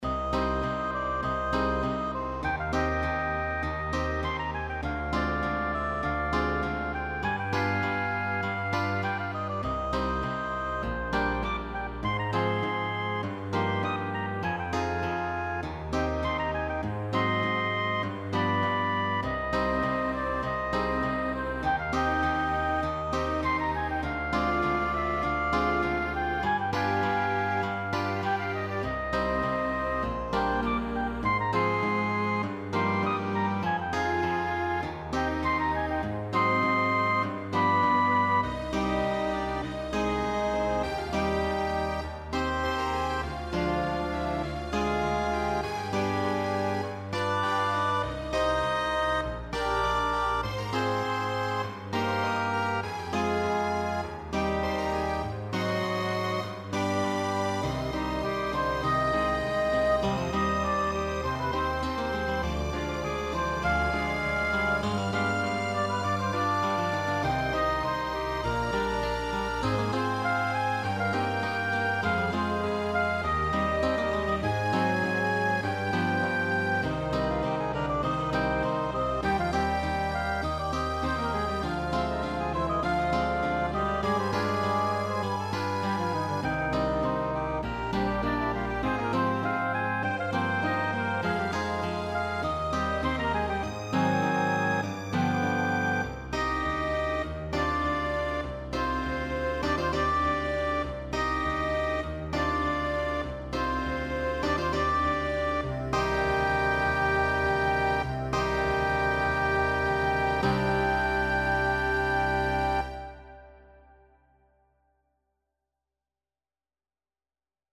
Prologue-Tango-Apasionada-Tango-Orkest-NL-Meespelen.mp3